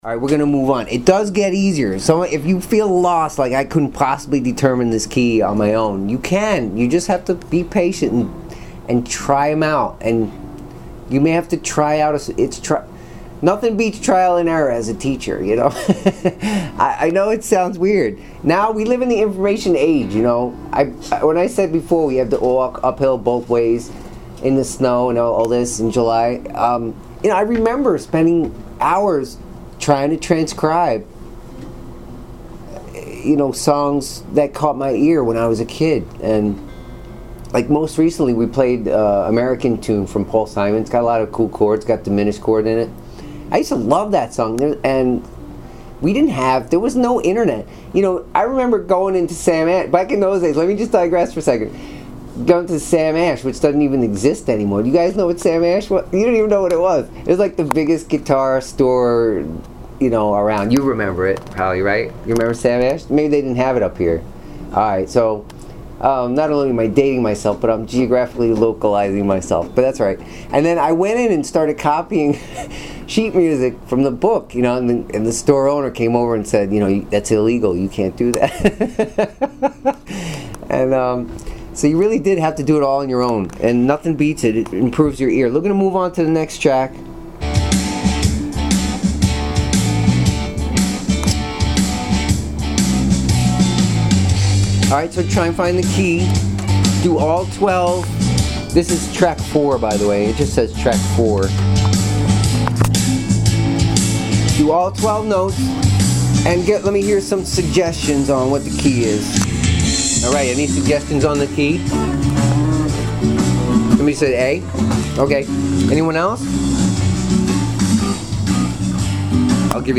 Live Lesson Presentation
how-to-play-to-jam-tracks-3.mp3